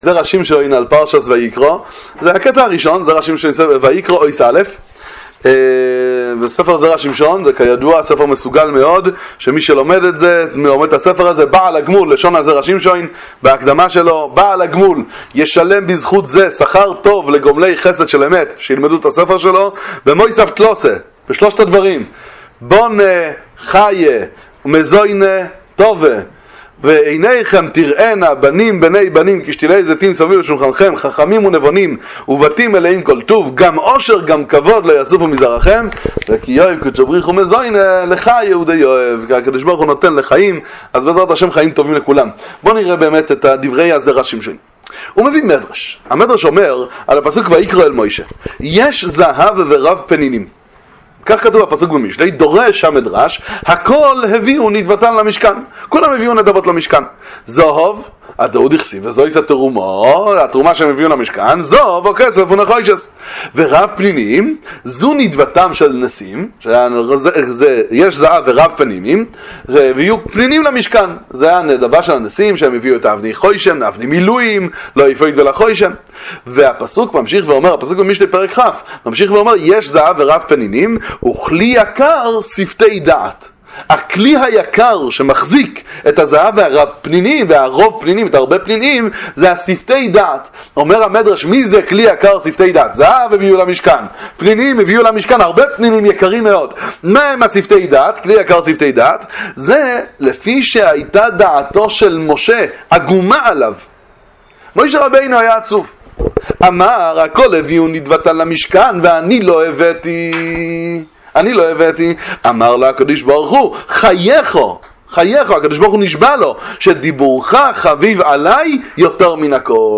בלי עגמת נפש… הדיבור שלך יקר יותר מהכול! דבר תורה לפרשת ויקרא מהספר המסוגל זרע שמשון אות א'